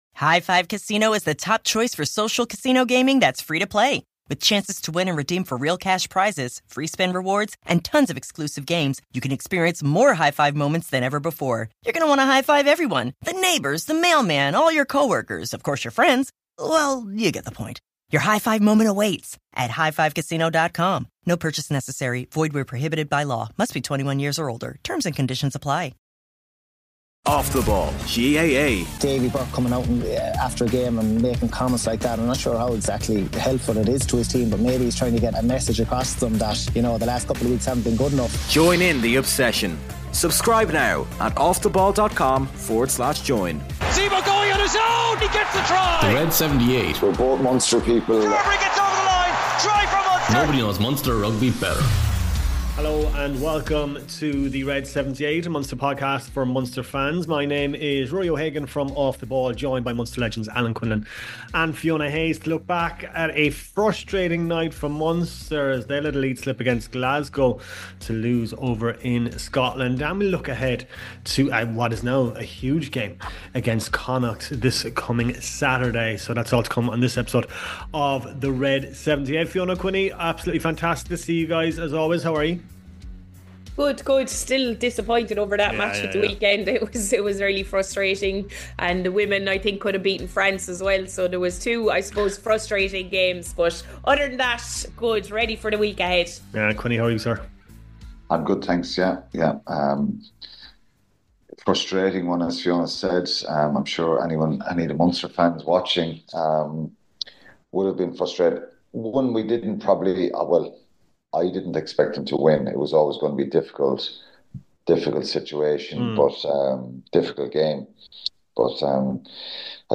Introducing 'OTB Rugby' - the home of Rugby from Off The Ball and OTB Sport, where you'll hear the voices of Brian O'Driscoll, Ronan O'Gara, Stuart Lancaster, Keith Wood, Alan Quinlan, Stuart Barnes, Andy Dunne and many more besides.
From legends interviews to live roadshows, analysis, depth charts, reviews, and weekly news.